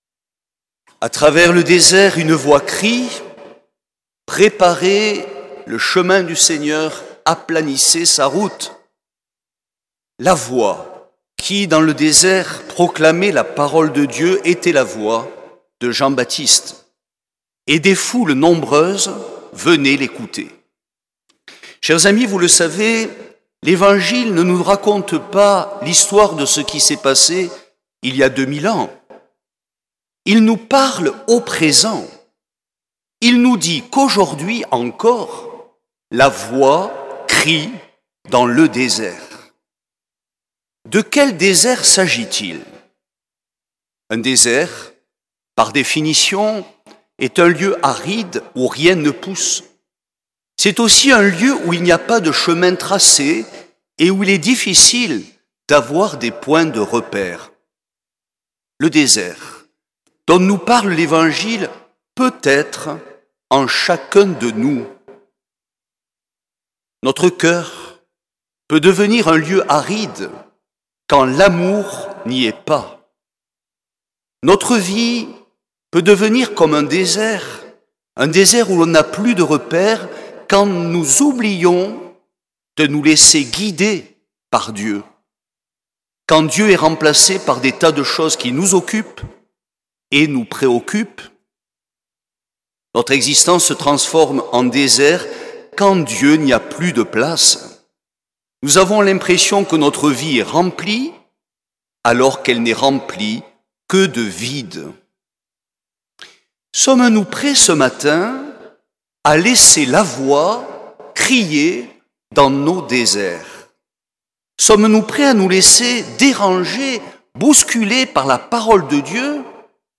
Homélie
2ème dimanche de l’Avent